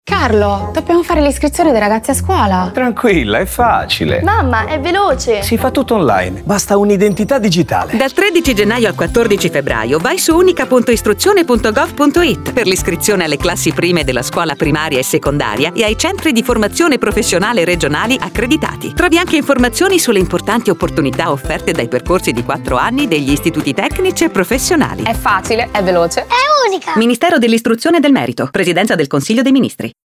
Lo spot radio